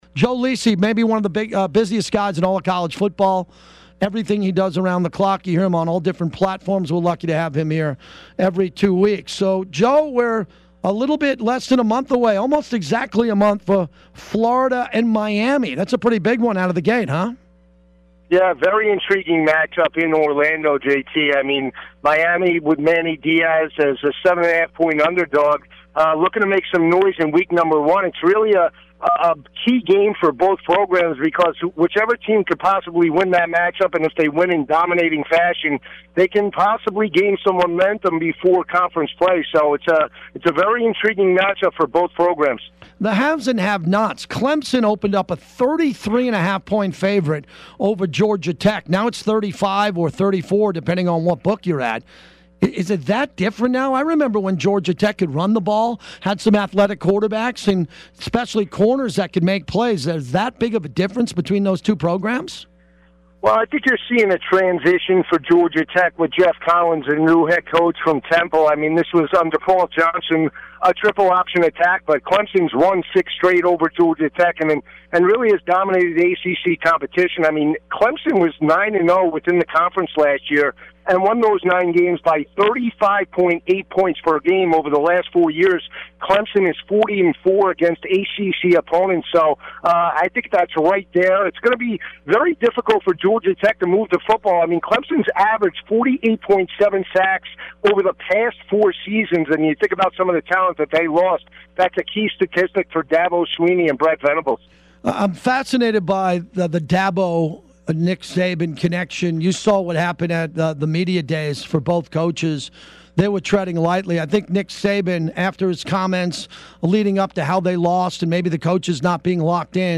My appearance with JT “The Brick” taking college football 2019 previews on Fox Sports Radio.